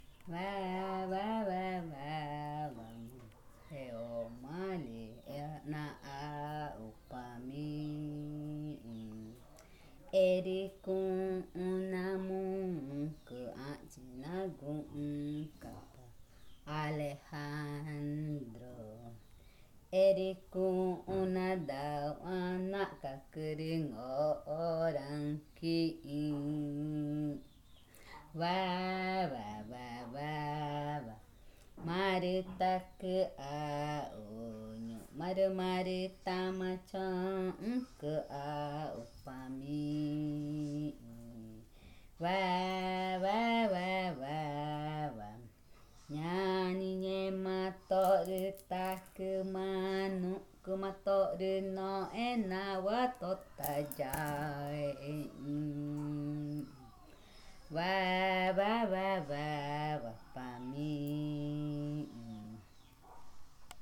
Cushillococha
Arrullo wawae
en Cushillococha (2023)